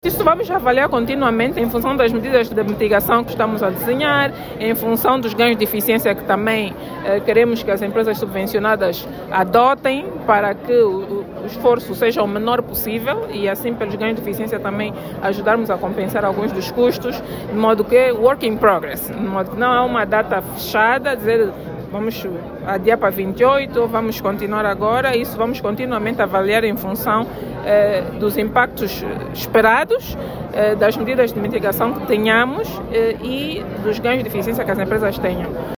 A Ministra das Finanças, Vera Daves de Sousa, esclareceu que enquanto este processo não for concluído não  haverá novo reajuste dos preços.